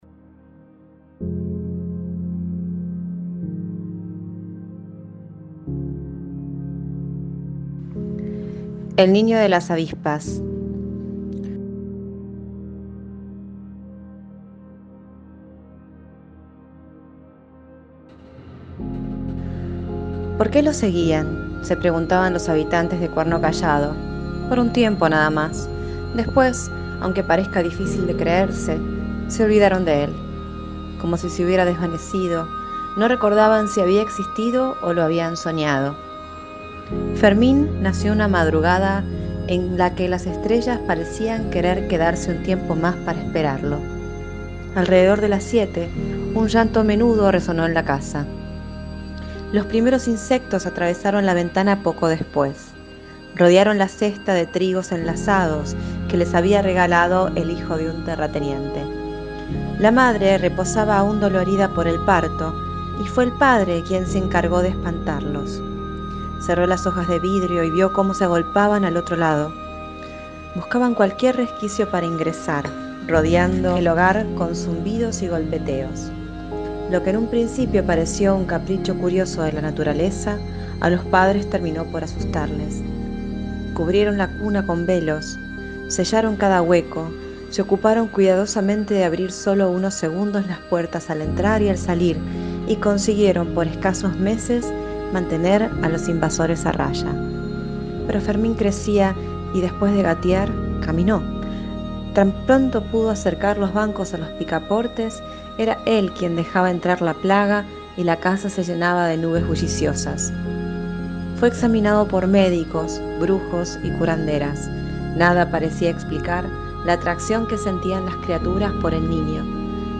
lee su cuento